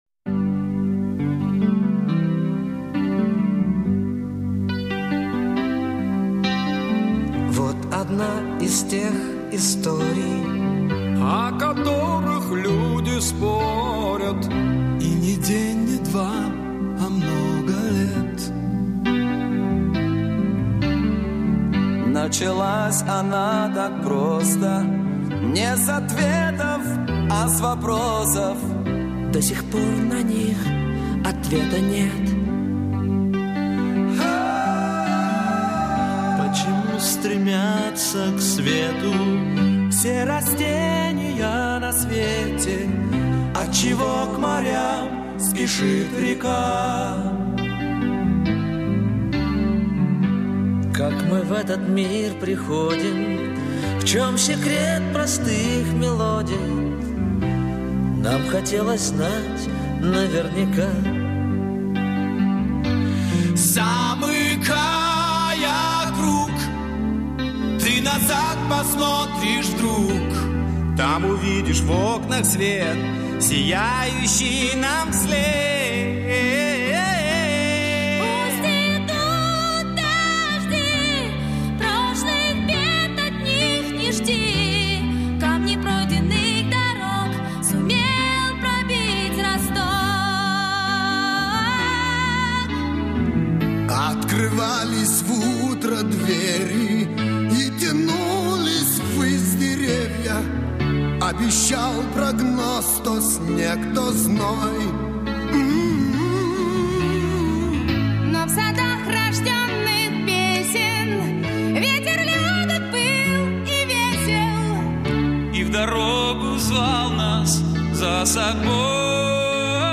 /128kbps) Описание: Гимн рок музыкантов.
Исполнили 25 ведущих рок музыкантов тех лет.